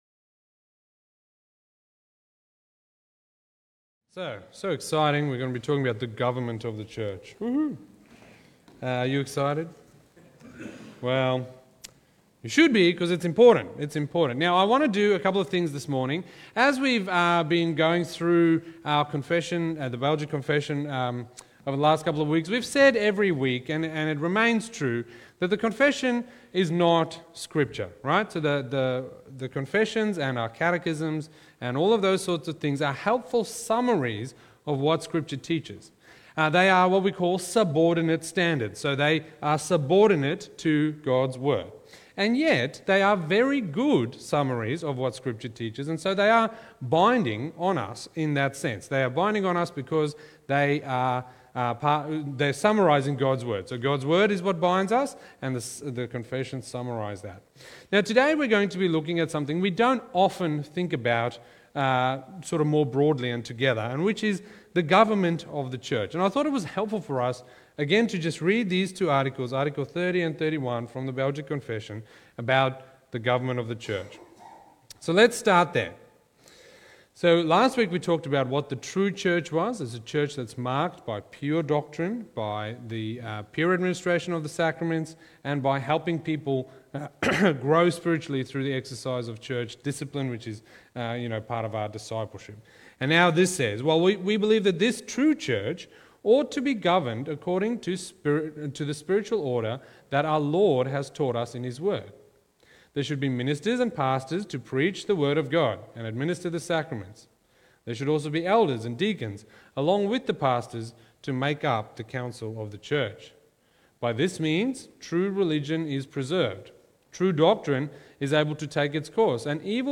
Sermons | Wonga Park Christian Reformed Church